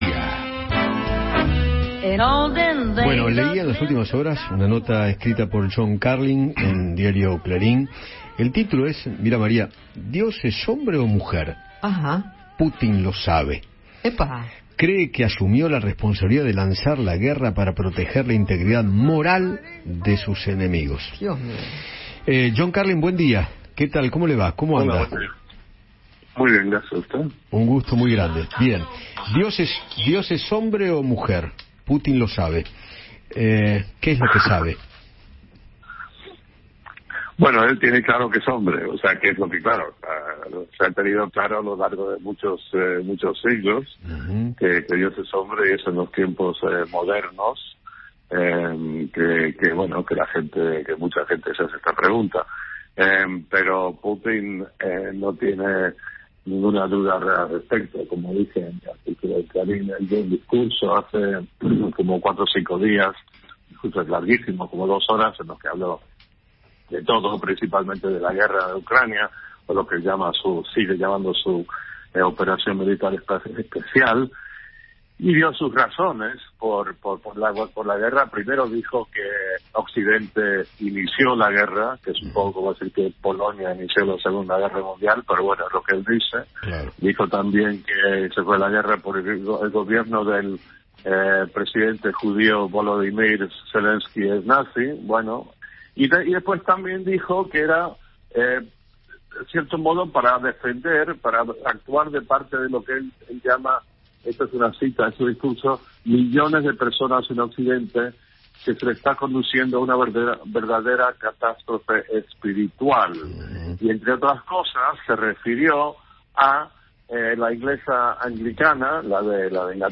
En Apuntes del Día, Eduardo Feinmann dialogó con John Carlin, columnista del diario Clarín, sobre la nota que publicó titulada “¿Dios es hombre o mujer?”.